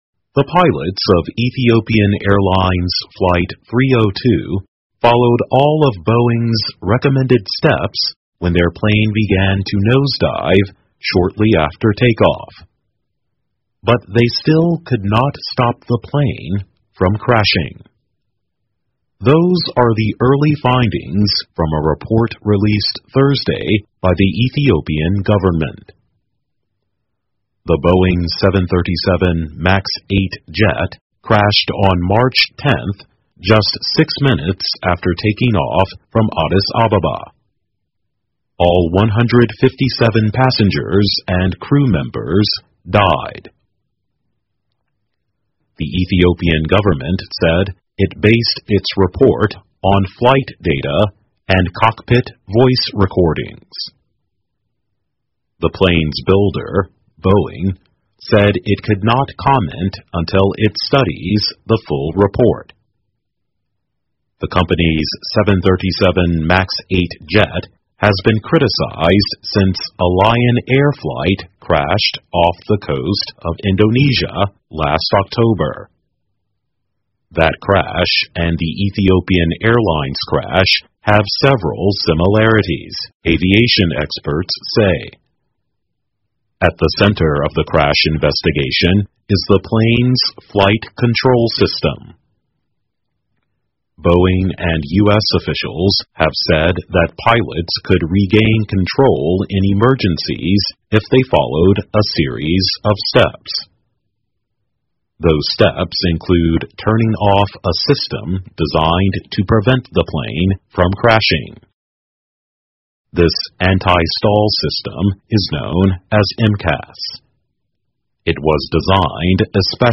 VOA慢速英语2019 报告称埃航飞行员在坠毁前遵循了波音公司指令 听力文件下载—在线英语听力室